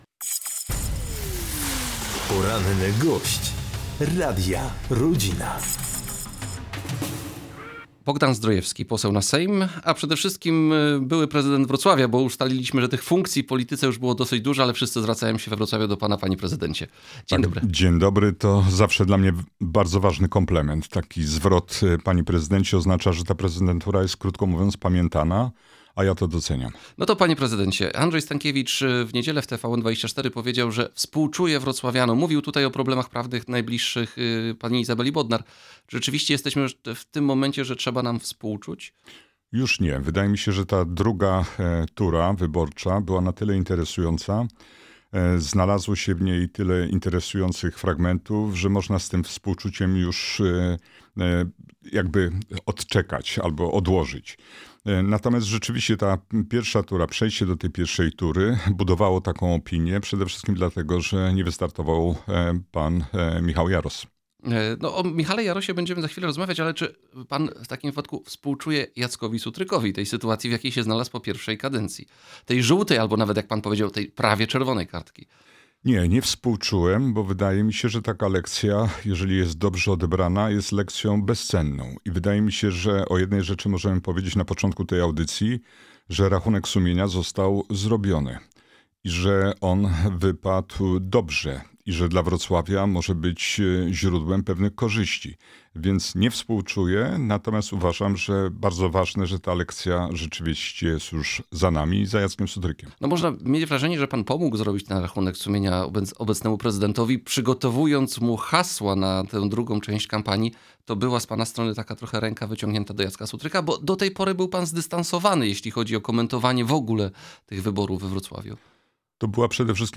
Naszym gościem jest Bogdan Zdrojewski, poseł na Sejm RP i były prezydent Wrocławia. Pytamy, czy godzi się z Jackiem Sutrykiem i popiera jego kandydaturę oraz, czy rekomendacja Michała Jarosa na Marszałka województwa dolnośląskiego jest ze strony Platformy Obywatelskiej aktem ekspiacji.